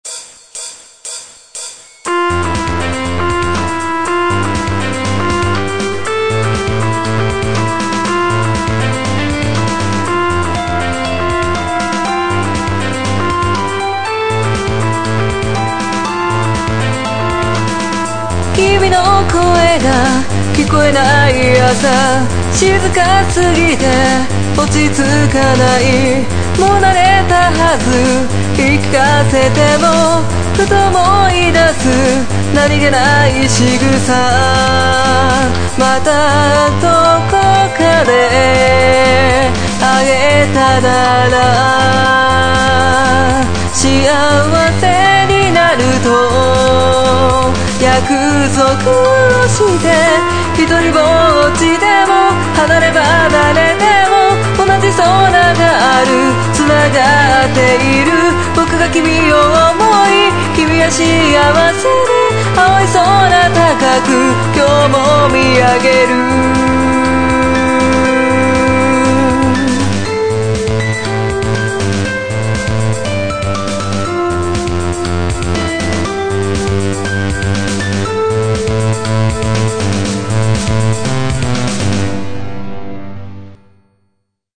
前回載せたサビだけだったものの長めになったヤツです。
22500Hzでモノラルの音源なので音質悪すぎて泣ける。
失恋ソングというよりは、お互い納得済みで別々の道に進んだ雰囲気ですかね。